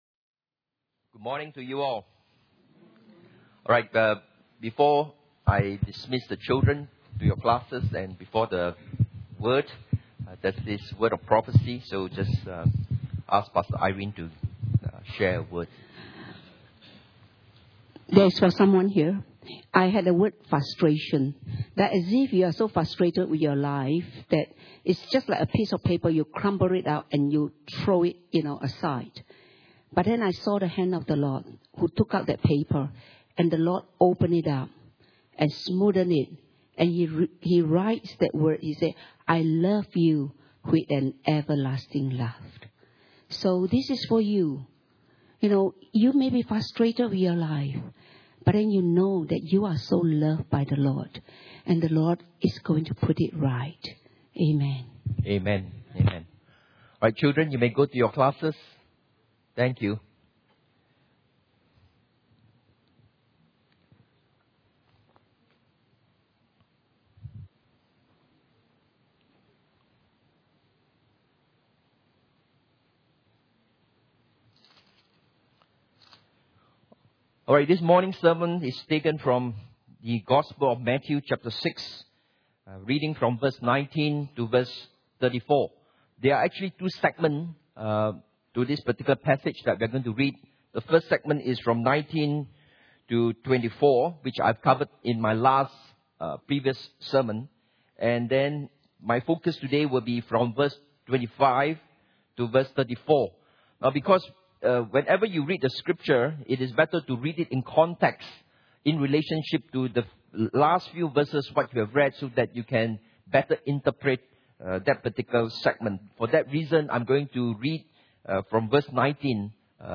Sermon on the Mount P15 - How to Overcome Worry.mp3